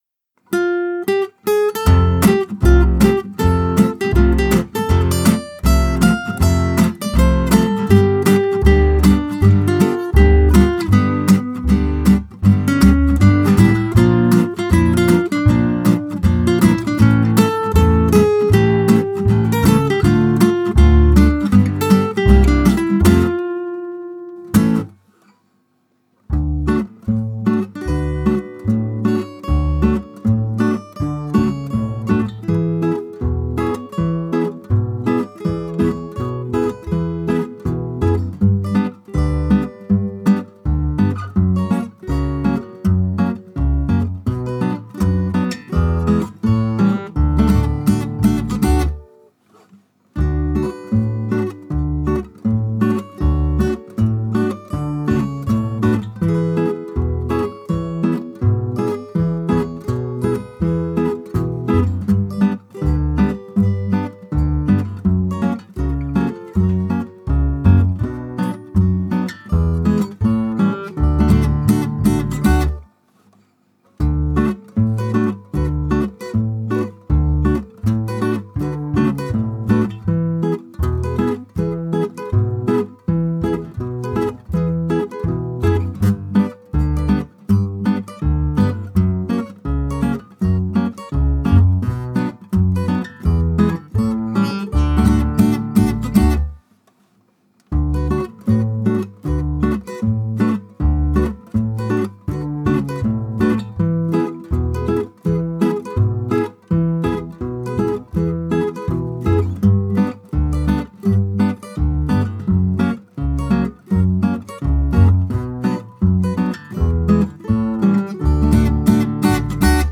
Version Instrumentale Sans Choeurs